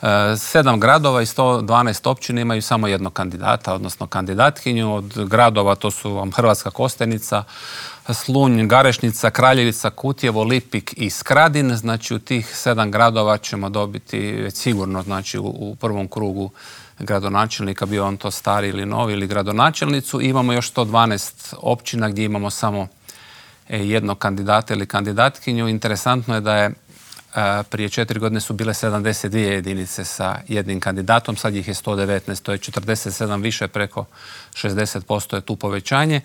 O nadolazećim izborima, pravilima glasovanja, ali i o izbornoj šutnji razgovarali smo u Intervjuu tjedna Media servisa s članom Državnog izbornog povjerenstva Slavenom Hojskim.